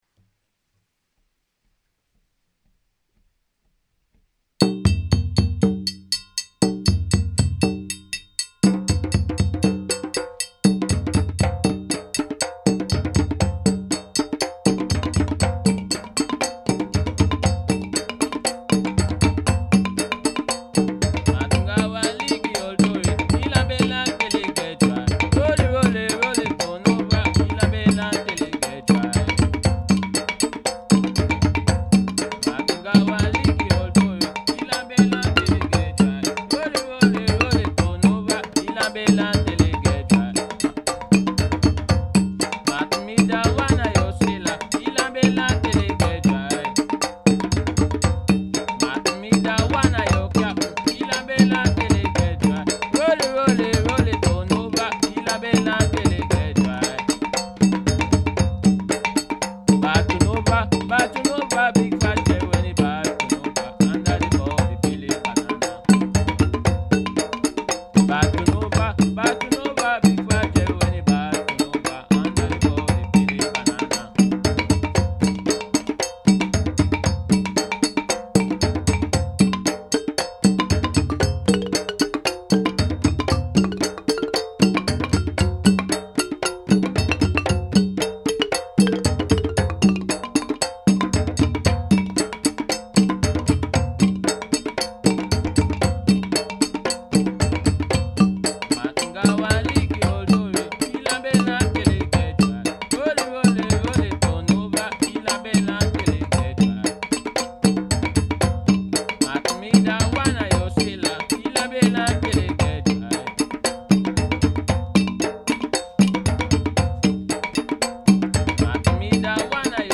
West Aftican Rythm with a Maroon Creole Song